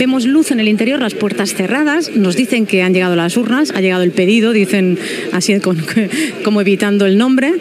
Informació des de davant dels Jesuïtes del carrer Casp on de bon matí han arribat les urnes per fer el Referèndum del dia 1 d'octubre
Entreteniment